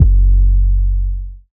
Index of /Antidote Advent/Drums - 808 Kicks
808 Kicks 09 F#.wav